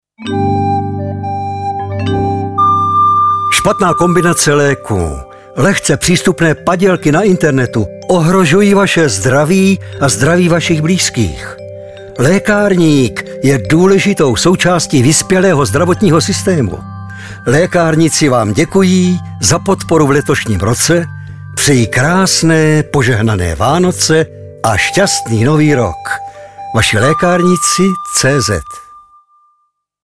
Pokračuje úspěšná kampaň na rádiu Frekvence 1 a rádiu Blaník. Hlasem Vašich lékárníků je i nadále charismatický herec Ladislav Frej.